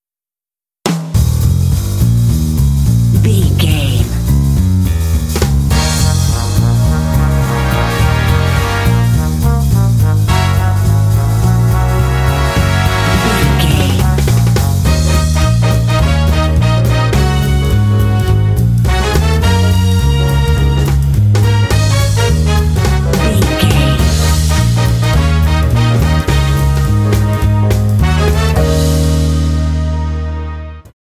Aeolian/Minor
E♭
energetic
groovy
bass guitar
piano
drums
brass
jazz
big band